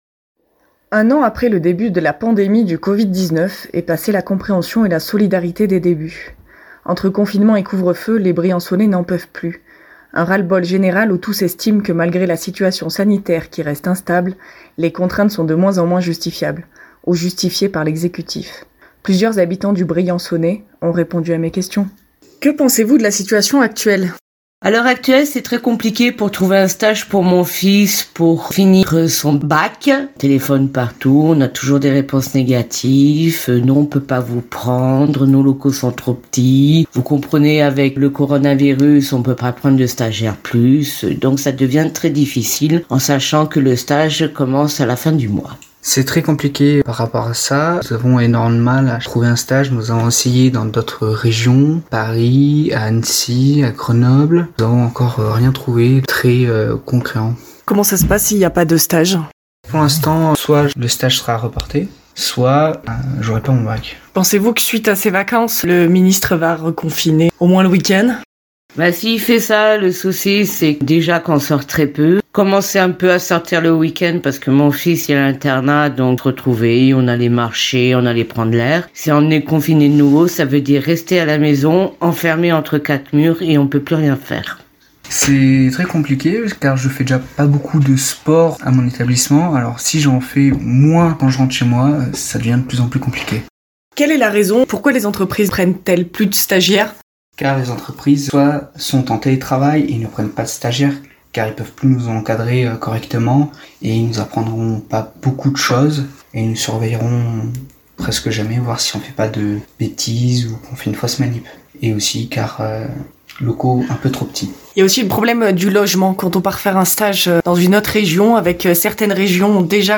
Plusieurs habitants du Briançonnais s'expriment